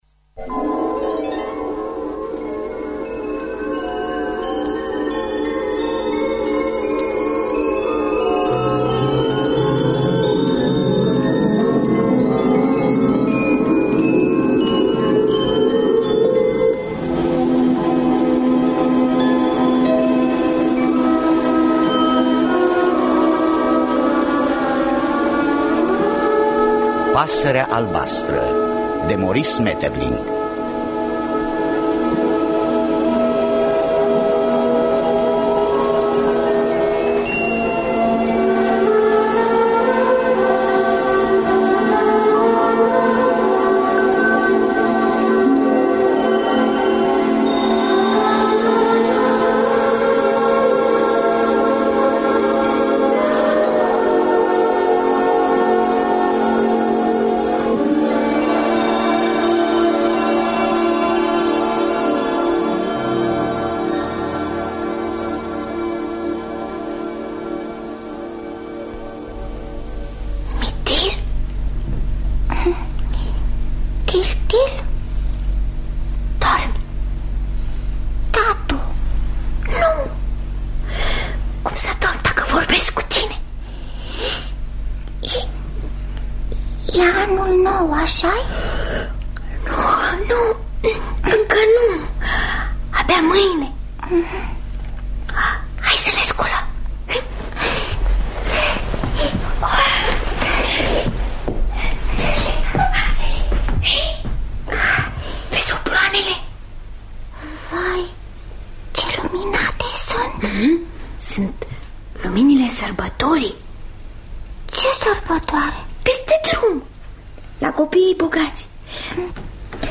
Adaptarea radiofonică şi regia artistică